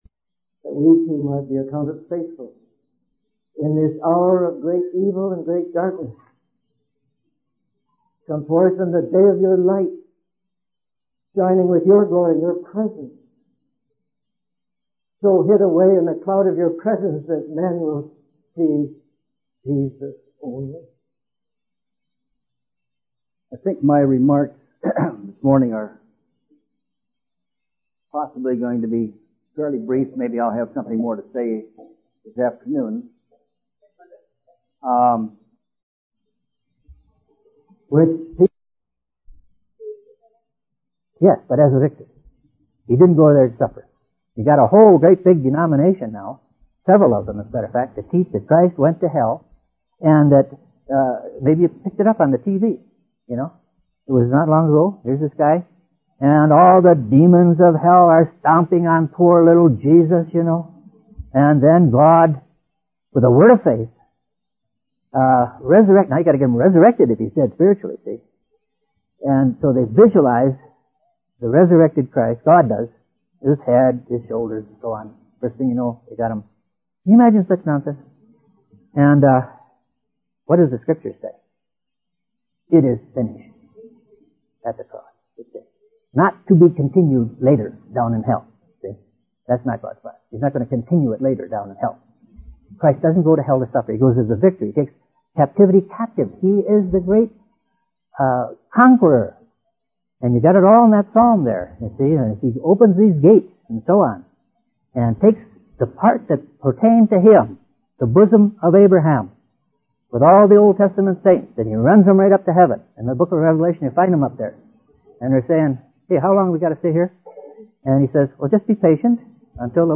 In this sermon, the speaker emphasizes the importance of being vessels of mercy in the world.